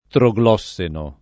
vai all'elenco alfabetico delle voci ingrandisci il carattere 100% rimpicciolisci il carattere stampa invia tramite posta elettronica codividi su Facebook troglosseno [ tro g l 0SS eno ] o trogloxeno [ tro g l 0 k S eno ] agg.